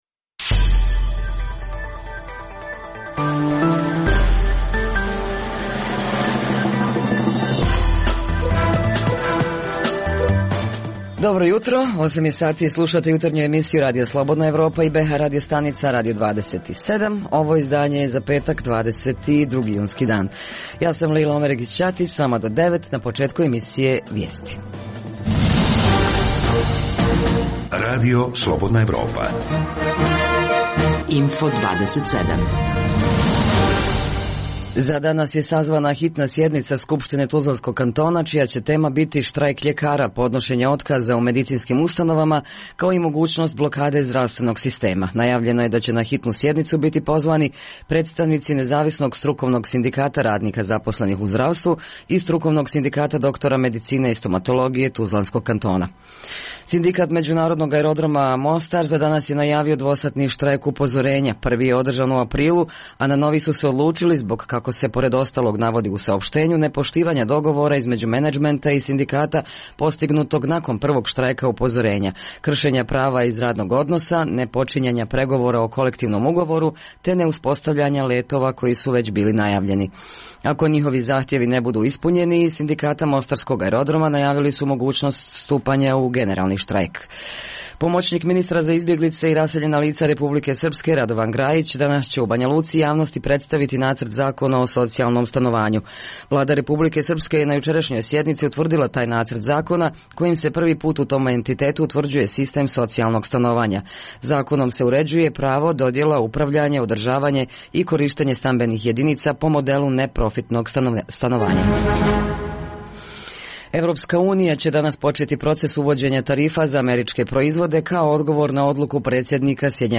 Sindikat Međunarodnog aerodroma Mostar najavio dvosatni štrajk upozorenja. U Bijeljini počinju radovi na uređenju kanala Dašnica, za šta je iz IPA fondova obezbijeđeno više od dva miliona eura. To su teme, o kojima javljaju naši dopisnici iz ta dva grada.